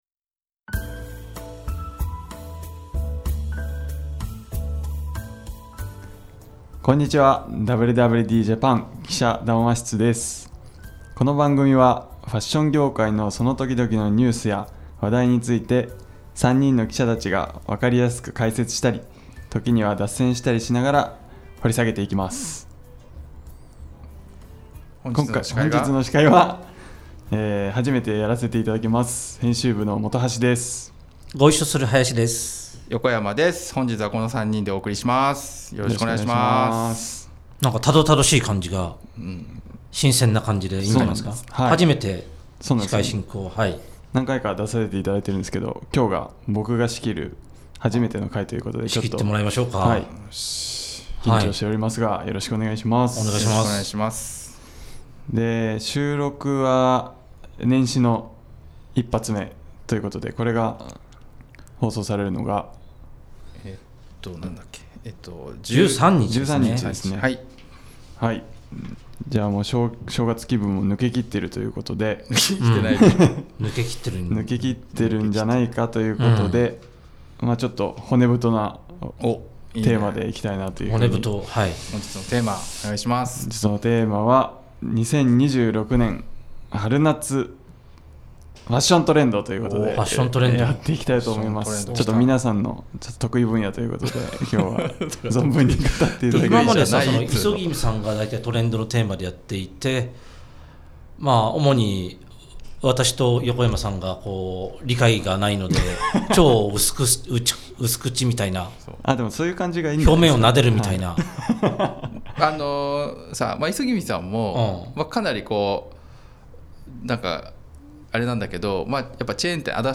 【連載 記者談話室】 「WWDJAPAN」ポッドキャストの「記者談話室」は、ファッション業界のその時々のニュースや話題について、記者たちが分かりやすく解説したり、時には脱線したりしながら、掘り下げていきます。